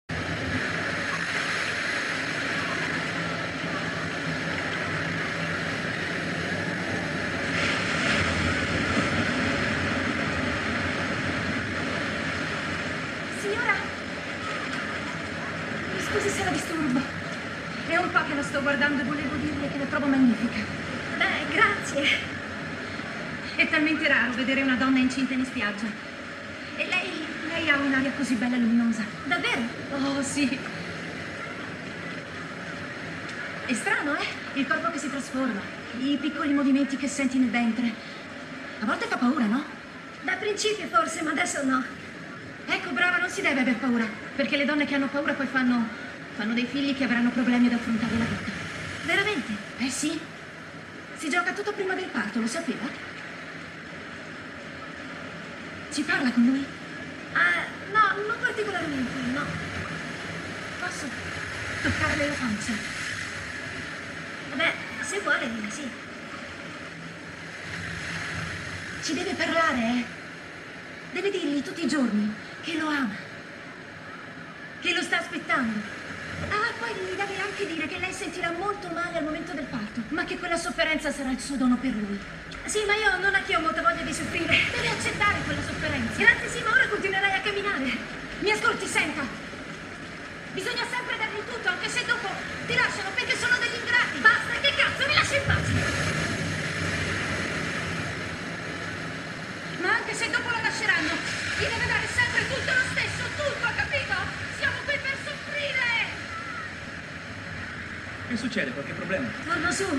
in cui doppia Marie Rivière.